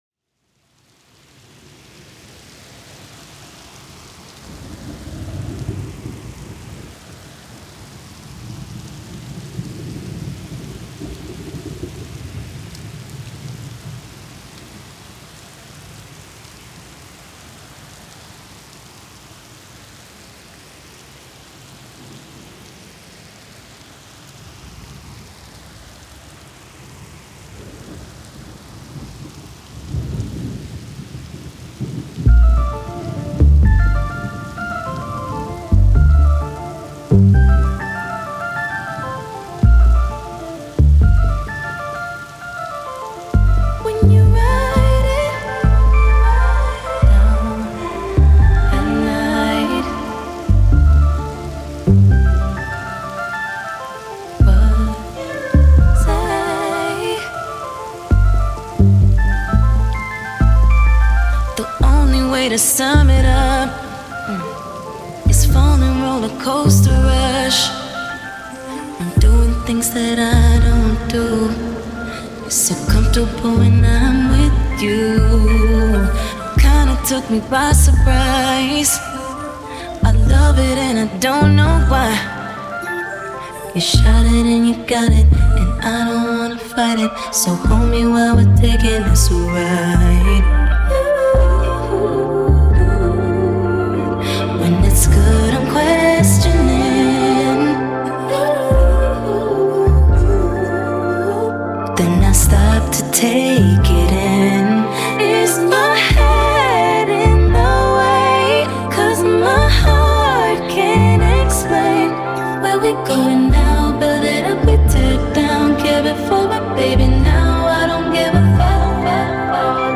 drumless
ambient electronic music